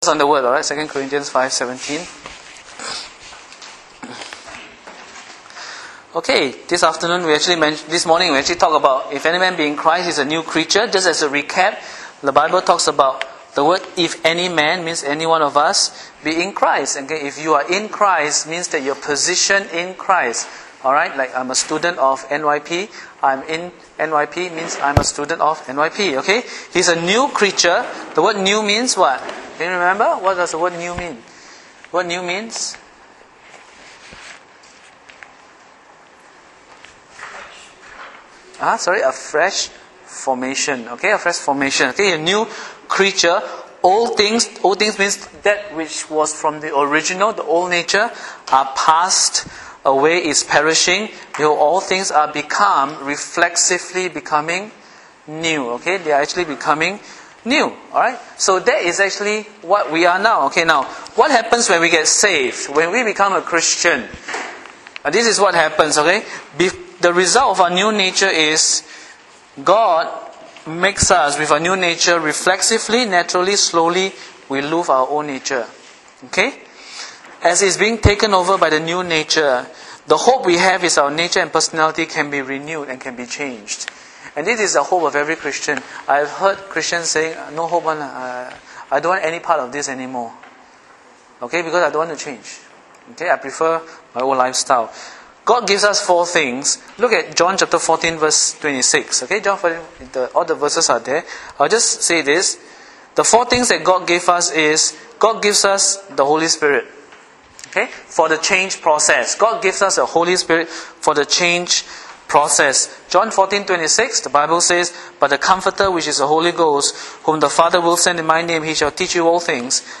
Date: 15 Jun 2011 (Church Camp)